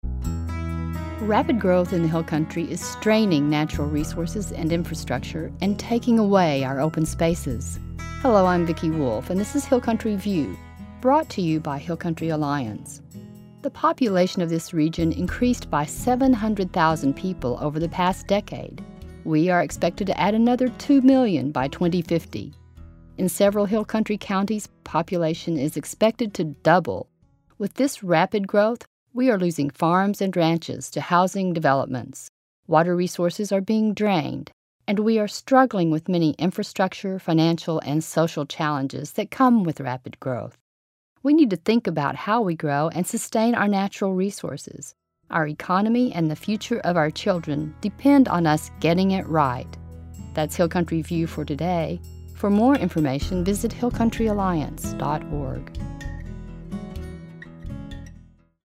60 Second Radio Spots – 2012